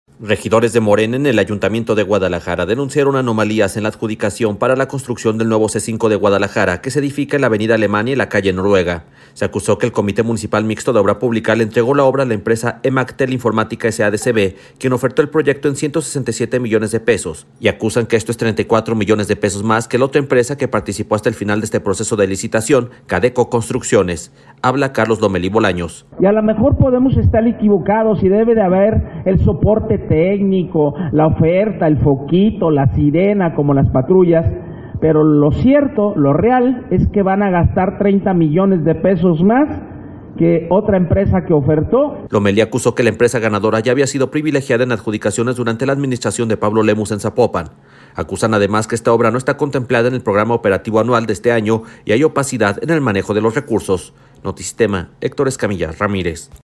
Habla Carlos Lomelí Bolaños: